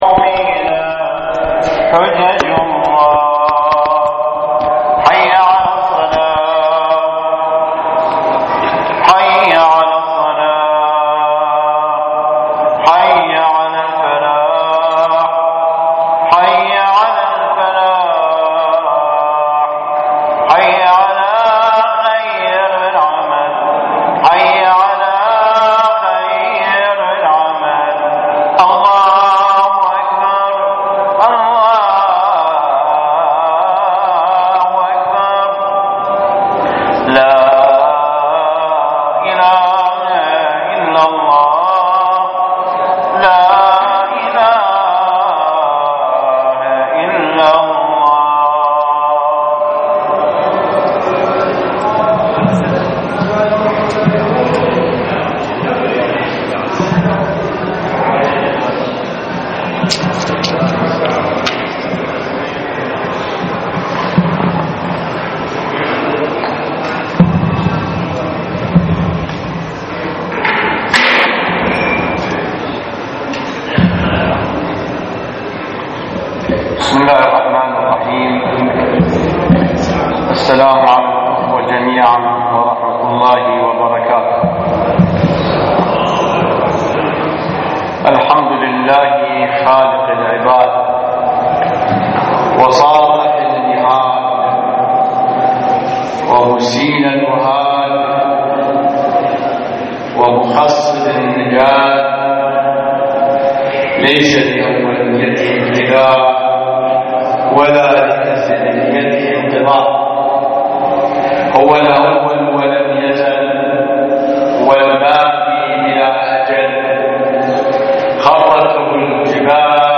صلاة الجمعة في مدينة الناصرية - تقرير صوتي مصور -